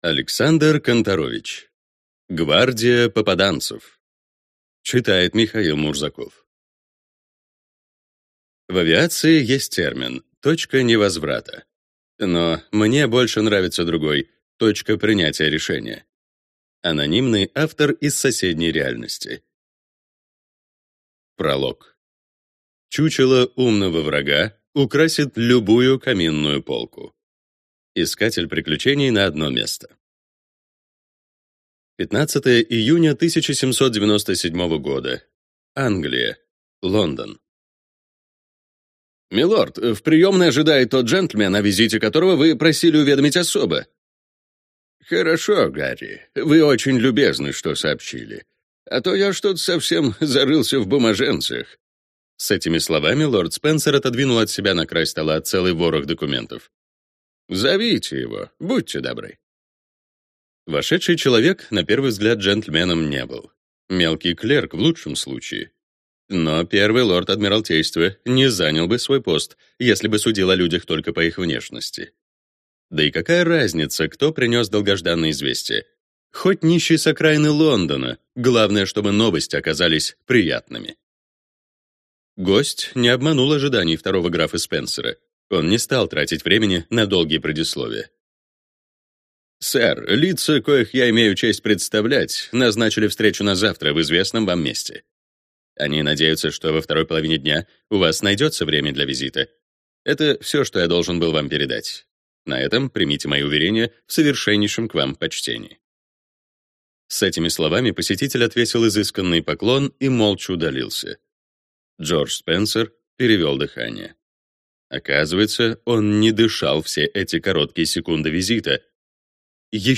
Аудиокнига Гвардия «попаданцев» | Библиотека аудиокниг